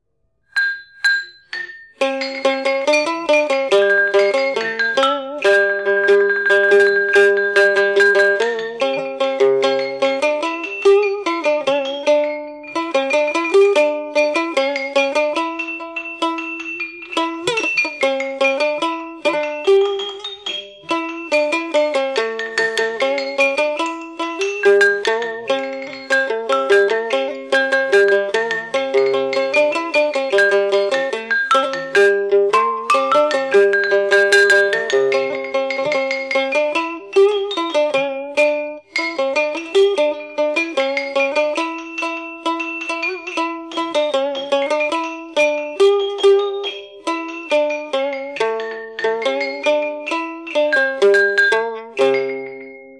Z-Headph.gif (478 bytes), Kim (kim khí)Z-Headph.gif (478 bytes) Ty (tơ), Trúc (tre nứa).Sau này, người ta chỉ dùng 8 nhạc khí, không đủ 8 màu âm như đã kể trên, nhưng vẫn gọi là Phường Bát âm: